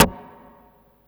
45 SD 1   -R.wav